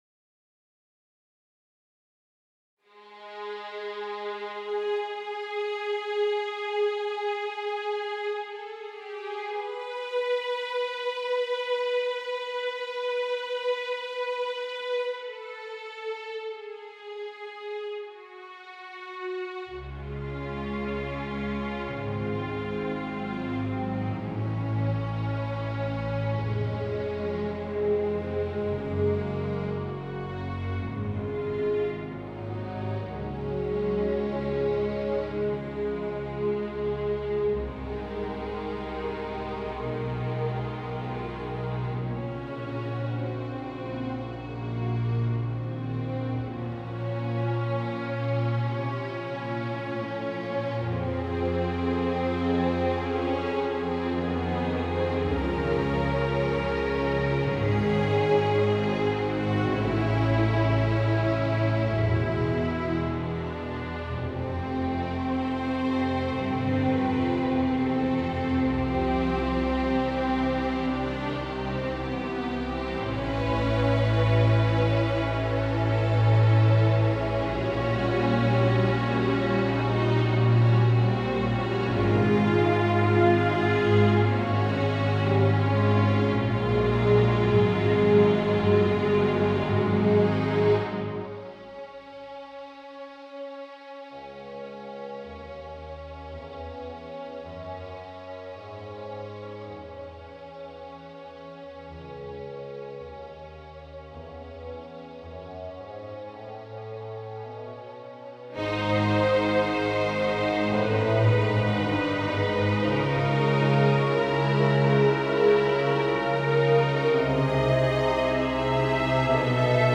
I just selected the sounds, and did some balancing between the instruments.
Obviously, only a very limited amount of articulations is used. Things like soft attacks and releases, and any vibrato intensity change and unwritten modulation change on long notes is missing.